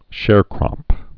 (shârkrŏp)